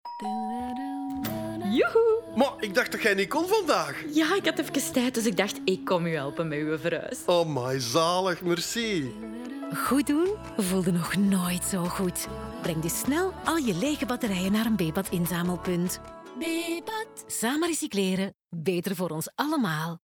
Sound Production & Sound Design: La Vita Studios
250324-Bebat-radio-mix-OLA--23LUFS-verhuis-NL-20.mp3